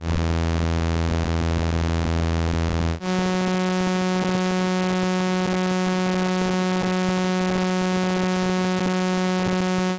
Stimmtöne Arkadische Botschaften I mp3
micro_guitar6XIV.mp3